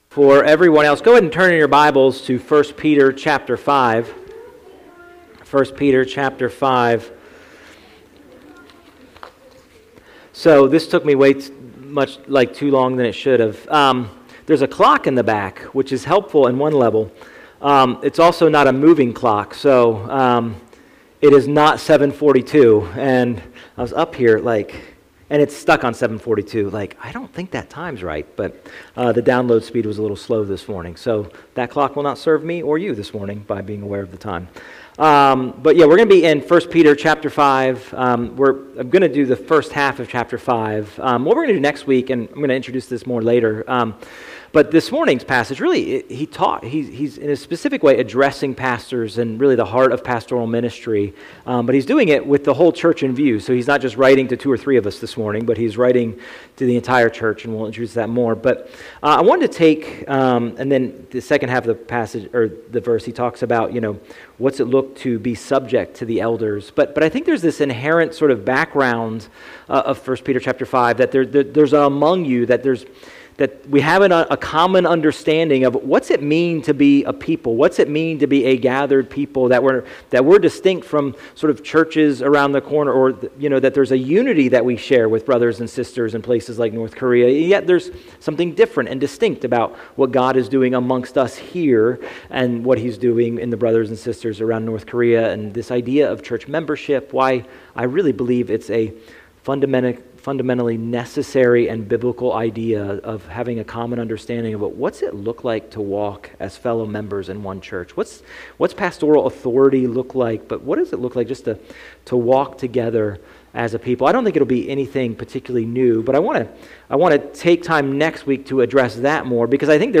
A message from the series "Higher."